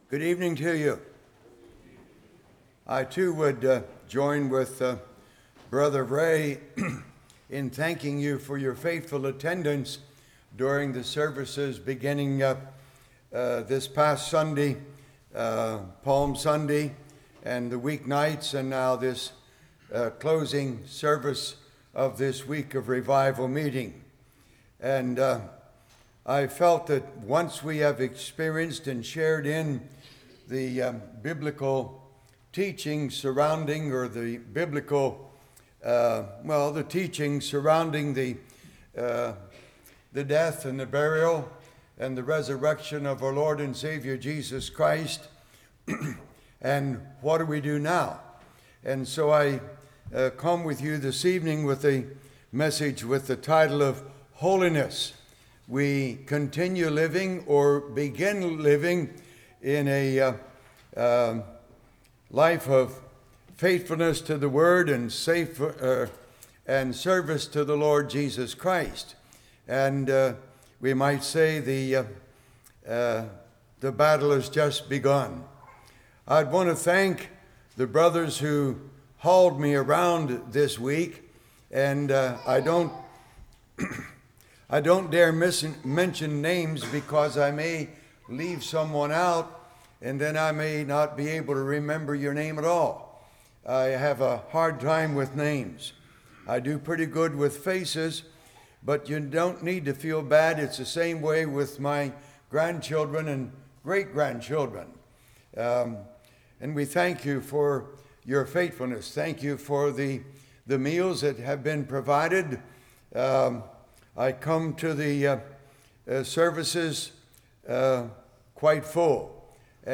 Spring Revival 2013 Passage: 1 Thessalonians 4:1-8 Service Type: Revival